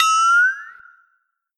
効果音 はてな
効果音 はてな (着信音無料)
hatena.mp3